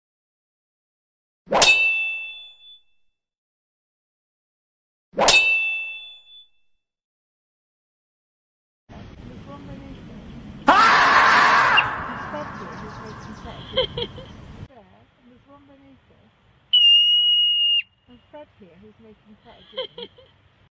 剣ナイフ戦い(シャキーン) 05 Swords, knives, fighting sound effects free download